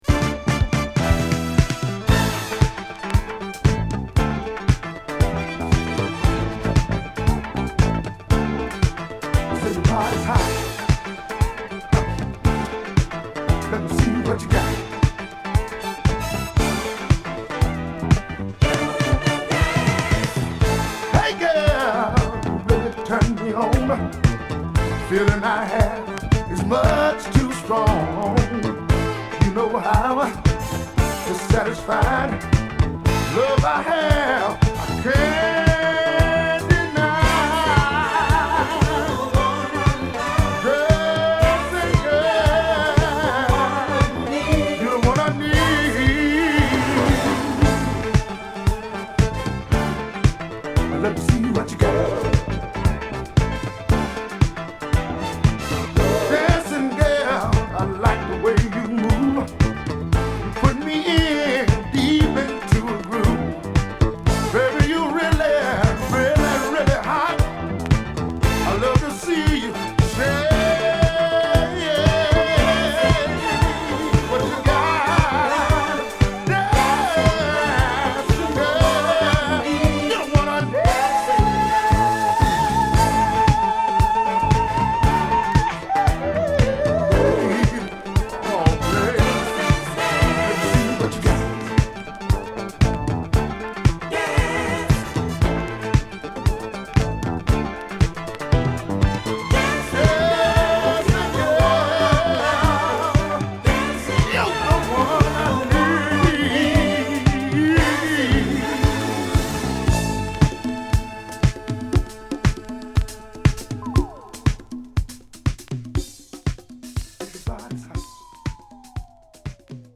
中盤のディスコブレイクも◎な自作のディスコブギー